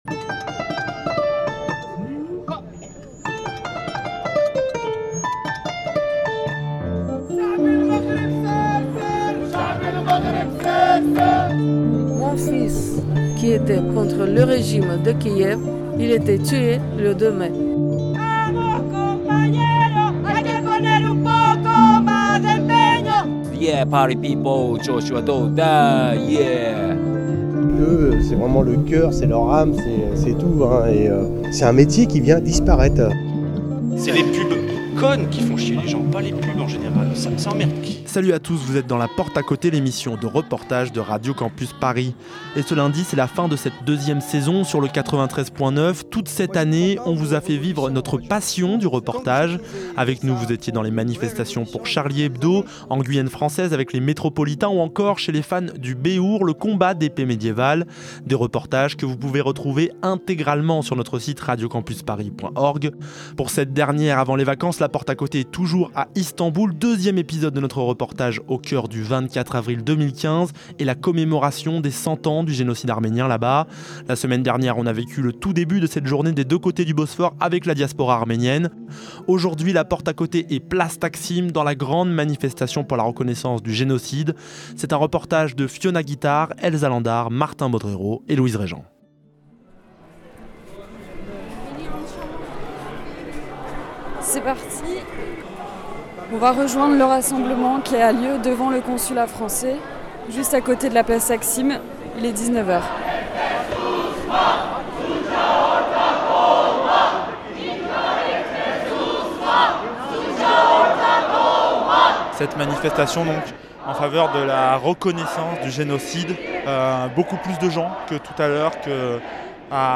Cette fois ci au coeur de la grande manifestation du soir dans le quartier de la Place Taksim.